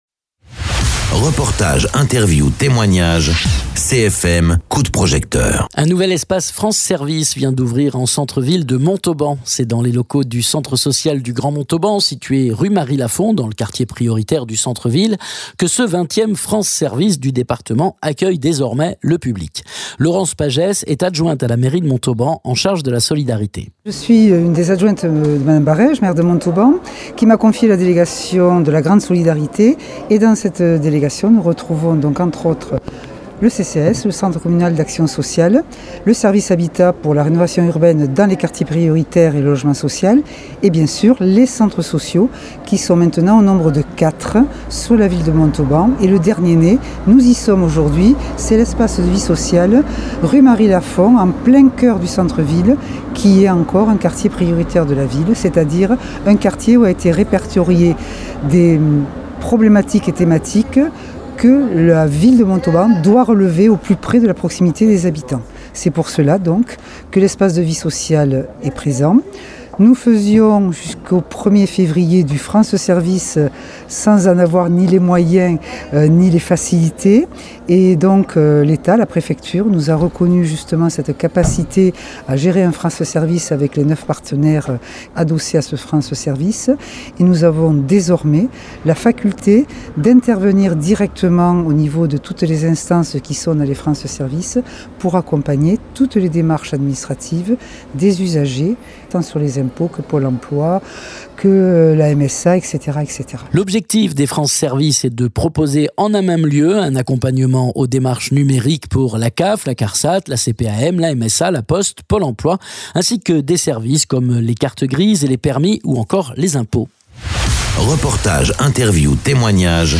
Interviews
Invité(s) : Laurence Pagès adjointe à la mairie de Montauban en charge de la solidarité